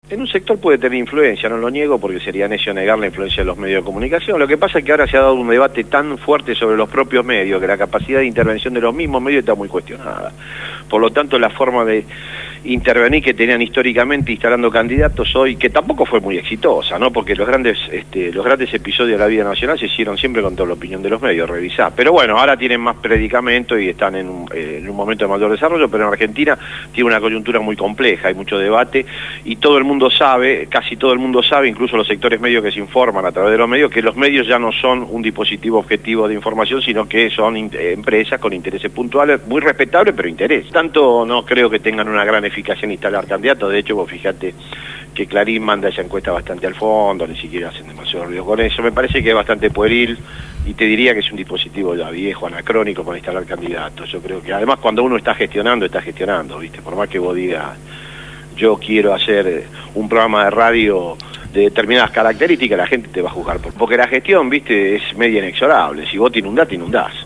Verborrágico, locuaz.